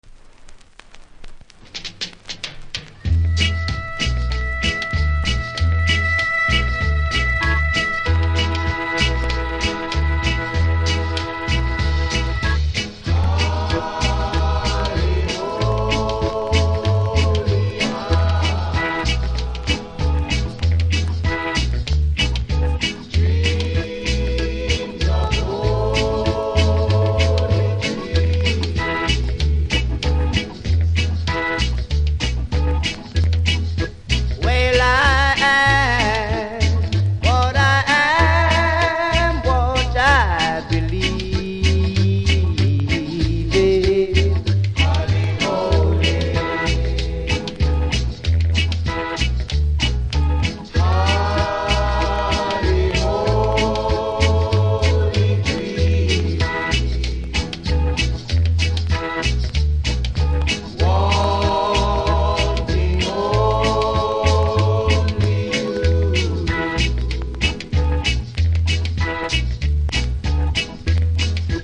盤に歪みありますがプレイは問題無いレベル。